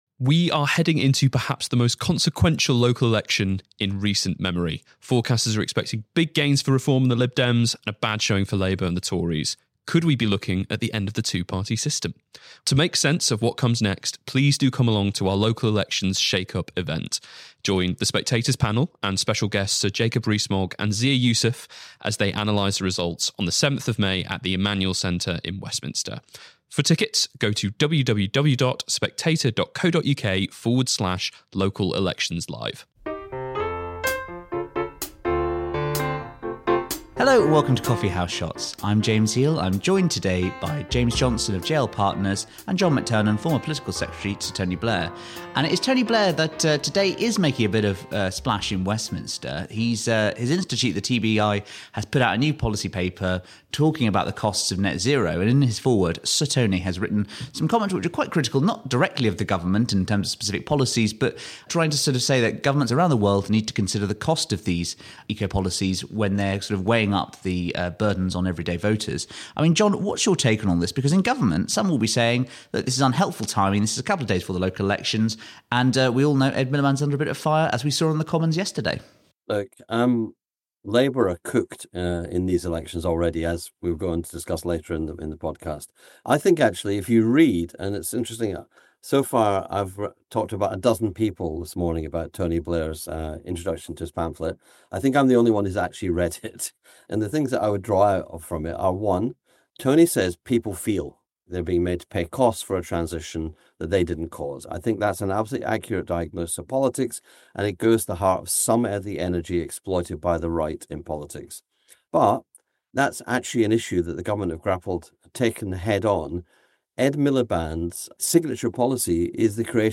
News, Politics, Government, Daily News